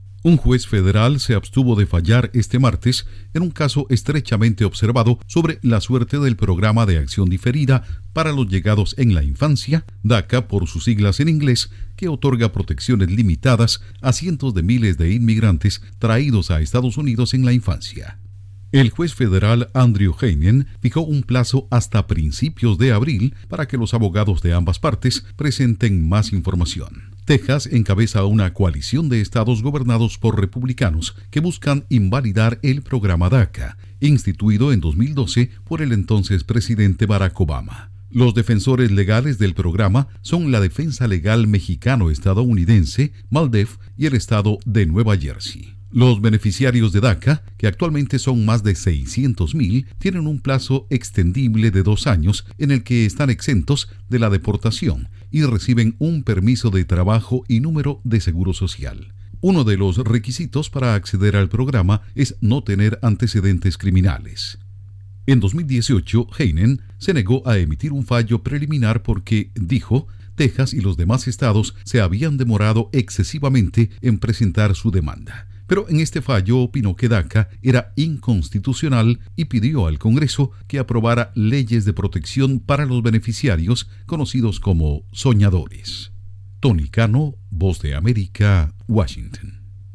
Juez de Estados Unidos aplaza fallo sobre programa de migrantes DACA. Informa desde la Voz de América en Washington